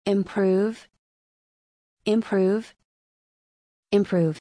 /ɪmˈpruːv/